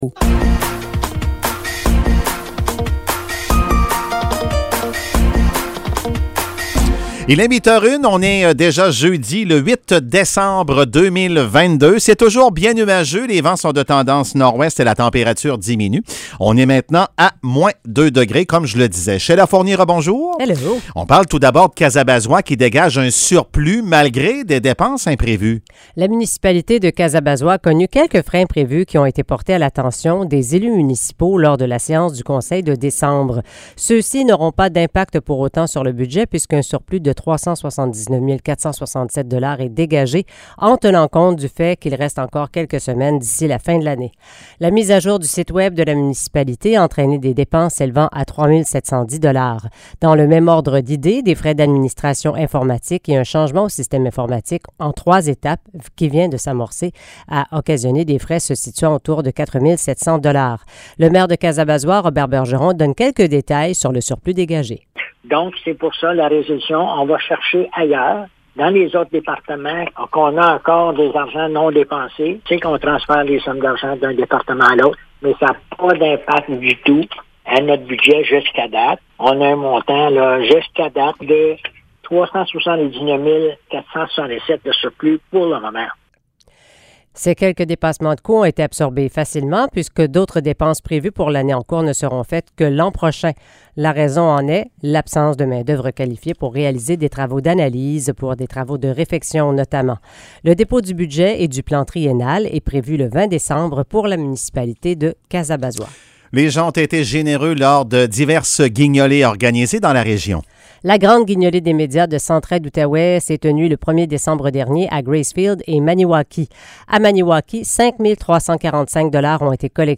Nouvelles locales - 8 décembre 2022 - 8 h